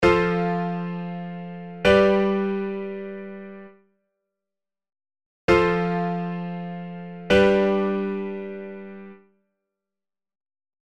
コードF⇒Gと進行する場合を見てみます。
F⇒Gはベースが上がっているので、GのトップノートDを1オクターブ下に持って行きます。